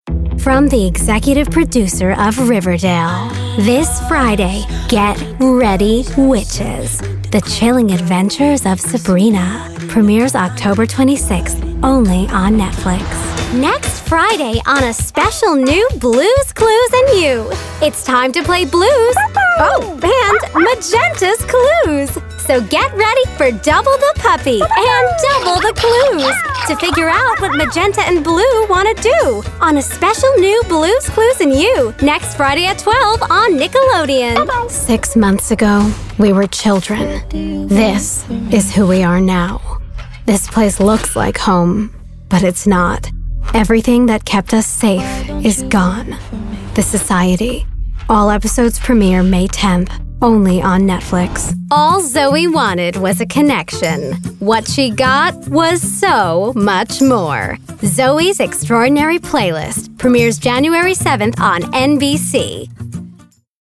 new york : voiceover : commercial : women